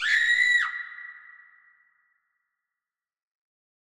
Vox (13).wav